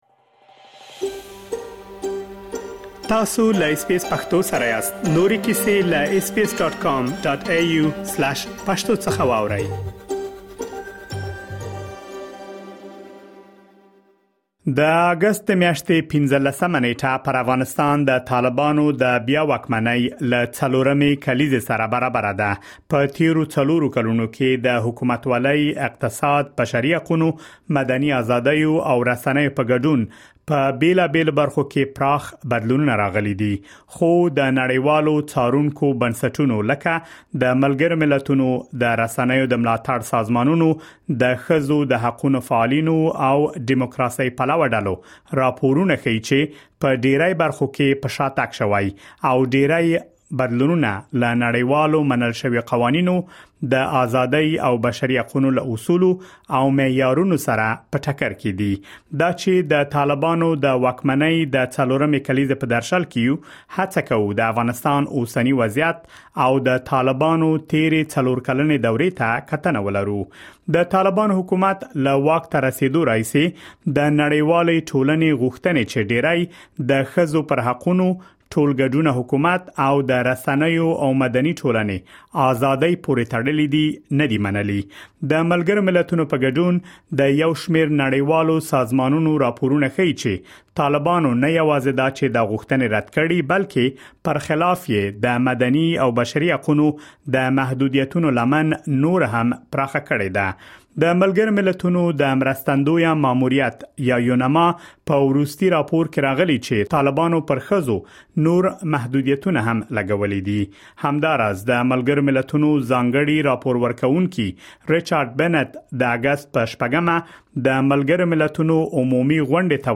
په تېرو څلورو کلونو کې د حکومتوالۍ، اقتصاد، بشري حقونو، مدني ازادیو او رسنیو په ګډون، په بېلابېلو برخو کې پراخ بدلونونه راغلي دي. په دغه رپوټ کې مو د طالبانو څلور کلنې واکمنۍ او په افغانستان کې روان وضعیت ته کتنه کړې ده.